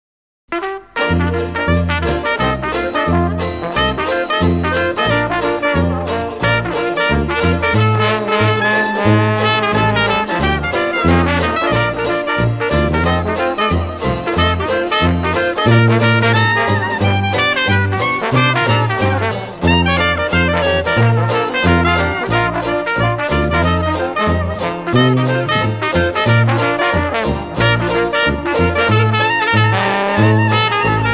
Jazz, Swing Bands
Dixieland jazz band plays happy foot-tapping, Trad Jazz.